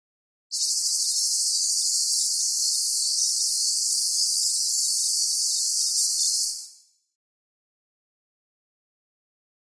Flash Readout High Frequency Rattle Drone with Shimmer